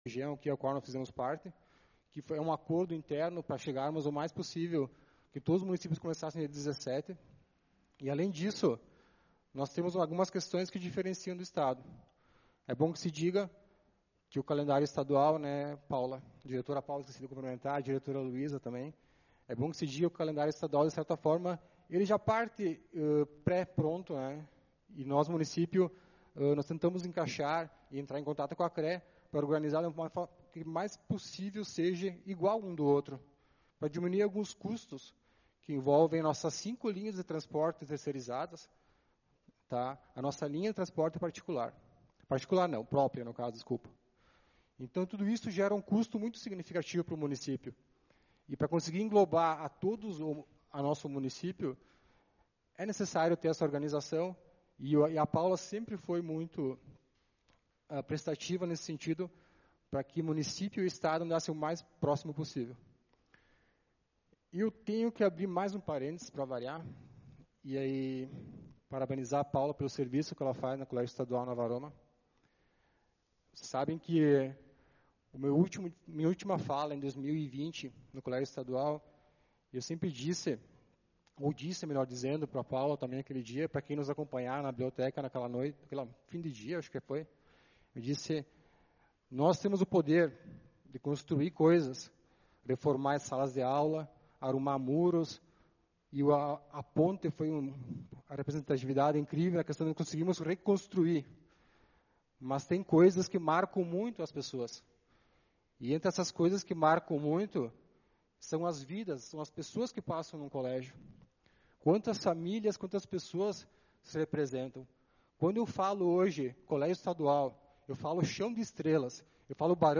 Sessão Ordinária do dia 12/02/2025